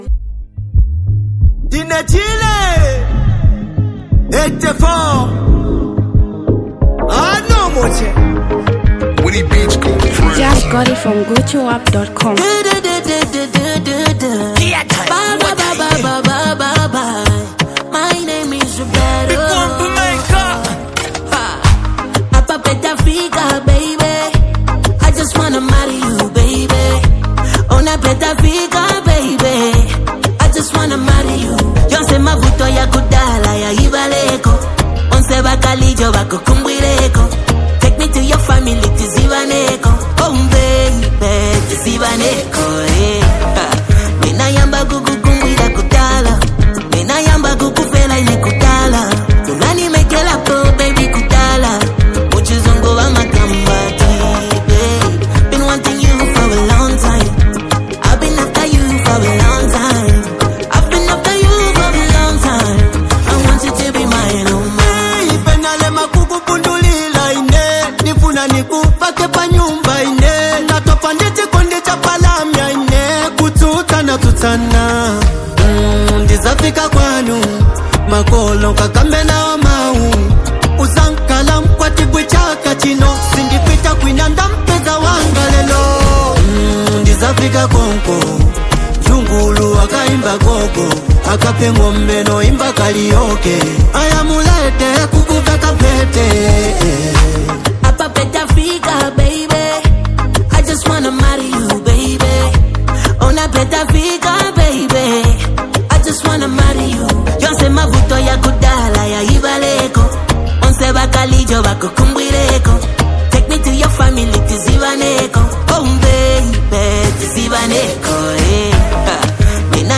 Zambian Mp3 Music
Eastern up talented singer
powerful melodic sound